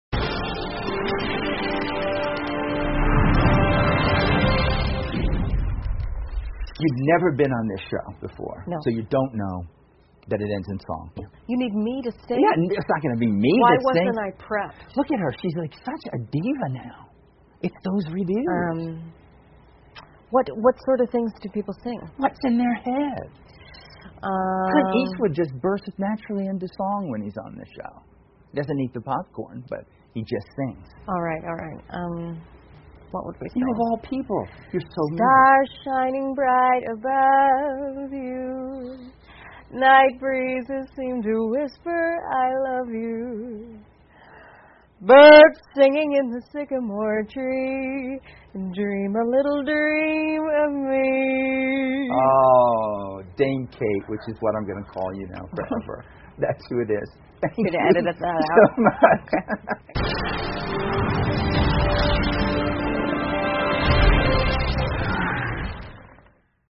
访谈录 凯特·麦克金农谈捉鬼敢死队 听力文件下载—在线英语听力室